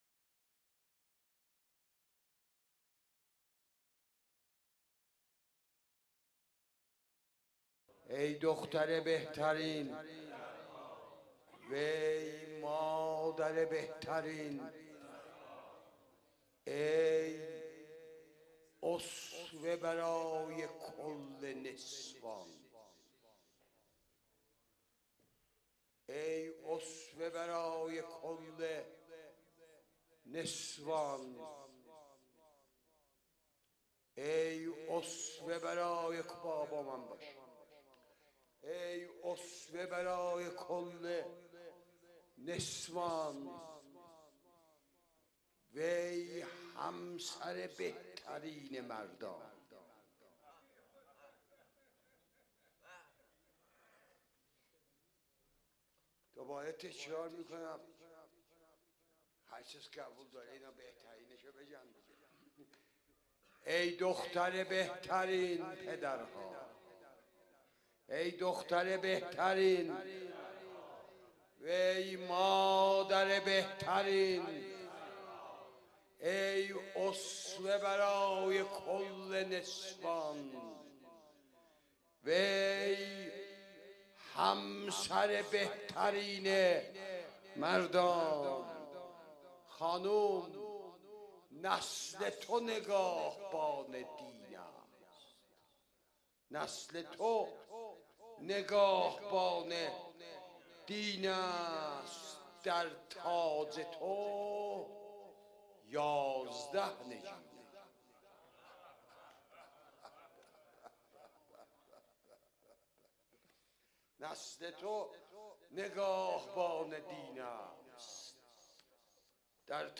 روضه | ای دختر بهترین سردار
روز دوم فاطمیه اول، اسفند ماه سال 1394